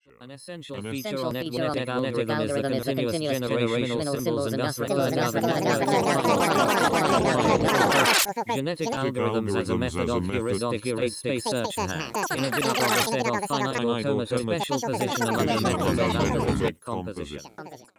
Motor and the string instrument. the body is resonating to the text rhythmic pattern. is it a doctor, who examines a patient on the subject of life?